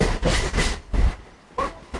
描述：Edited loop of train sound.
标签： trains rhythm loop
声道立体声